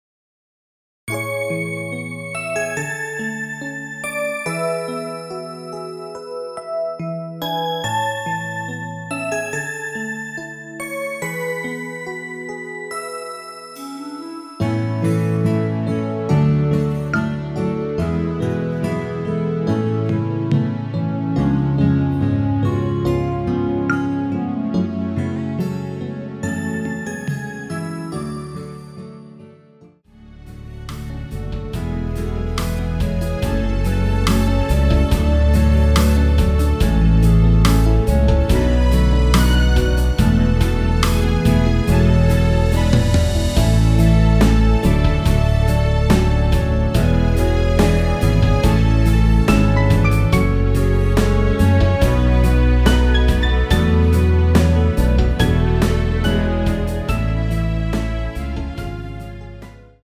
◈ 곡명 옆 (-1)은 반음 내림, (+1)은 반음 올림 입니다.
앞부분30초, 뒷부분30초씩 편집해서 올려 드리고 있습니다.
중간에 음이 끈어지고 다시 나오는 이유는
축가 MR